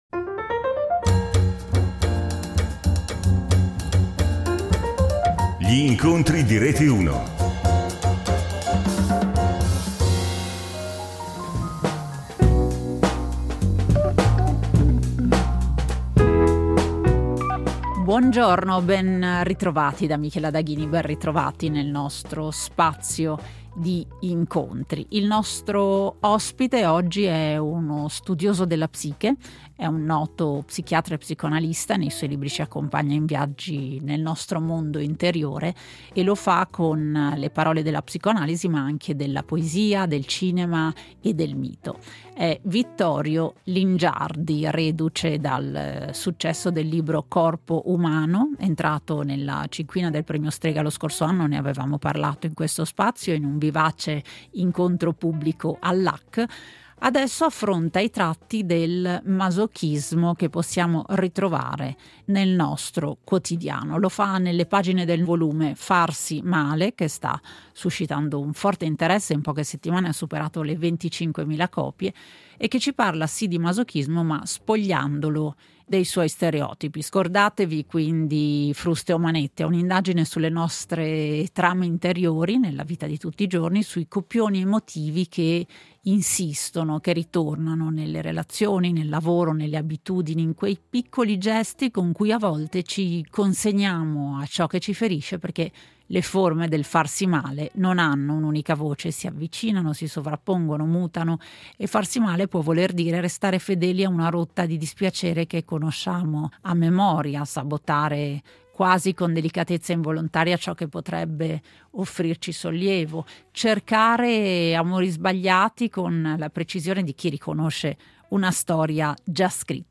Lo incontriamo nel suo studio, nel cuore della vecchia Milano, affacciato su una corte ricca di piante: due poltrone rosse, un tavolino d’epoca con oggetti che sembrano custodire storie, e pareti abitate da libri che non smettono di parlare a chi li ha scelti.